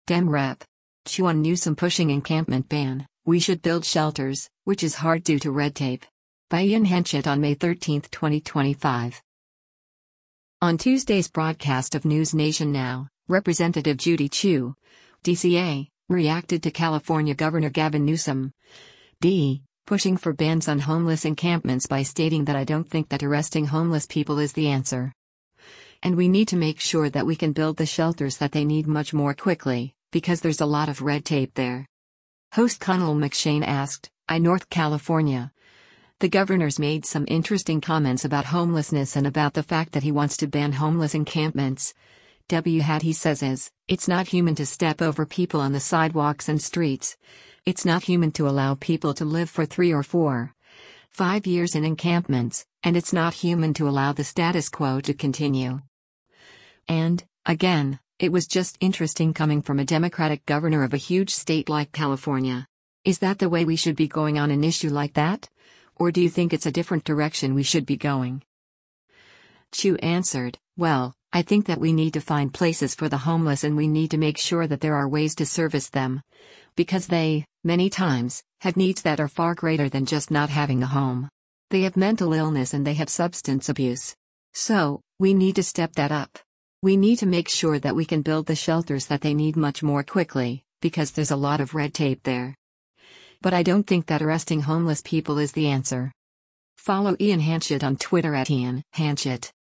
On Tuesday’s broadcast of “NewsNation Now,” Rep. Judy Chu (D-CA) reacted to California Gov. Gavin Newsom (D) pushing for bans on homeless encampments by stating that “I don’t think that arresting homeless people is the answer.”